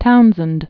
(tounzənd), Charles 1725-1767.